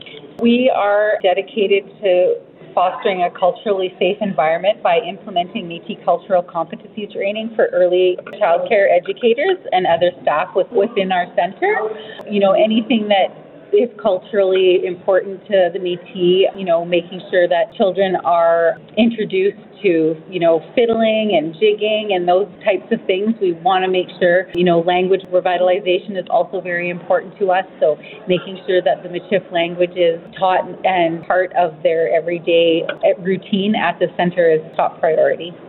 Bramfield says the focus is to create a culturally safe environment with the Métis Culture training implemented with early childcare educators and staff, ensuring that children are introduced to Métis fiddling, jigging and language revitalization with the Michif language.